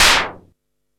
SIMMONS SDS7 6.wav